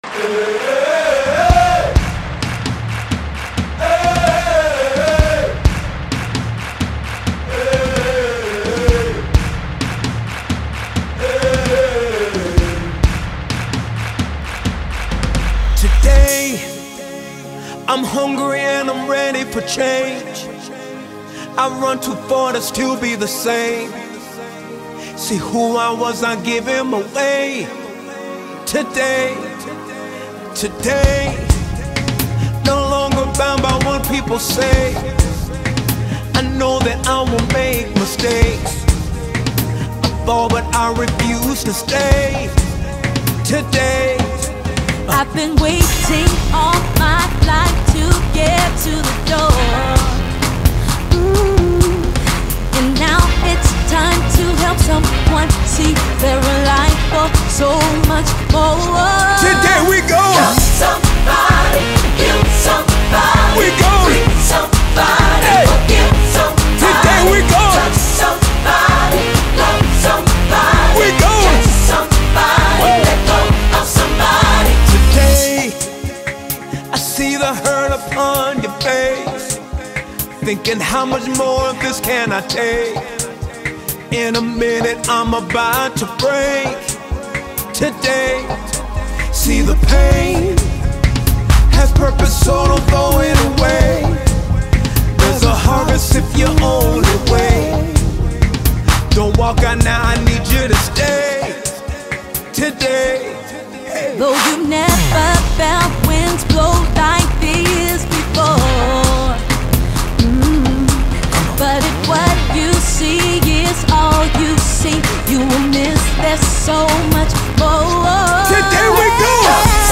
soul-lifting gospel song
” a soul-stirring gospel anthem from
Genre:Gospel